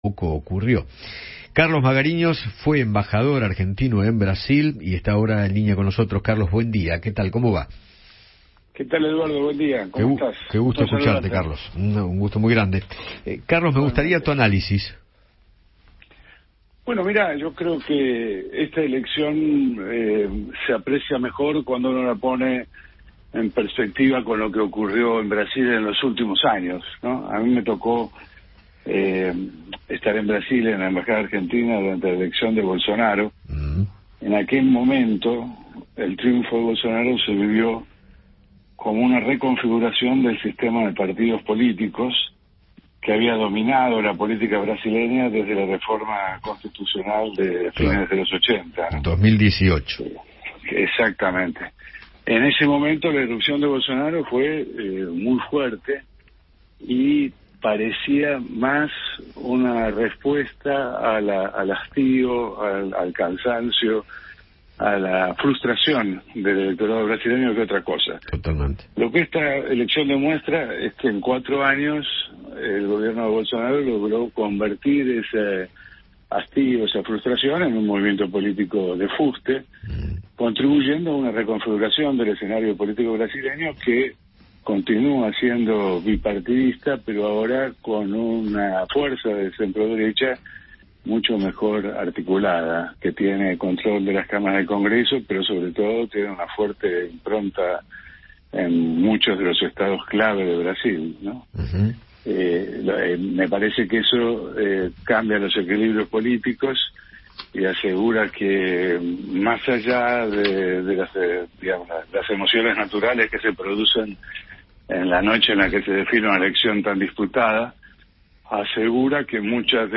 Carlos Magariños, ex embajador de Argentina en Brasil, habló con Eduardo Feinmann sobre la victoria de Luiz Inácio Lula da Silva en las elecciones presidenciales del país vecino y recordó el triunfo de Bolsonaro cuando le tocó estar en la embajada.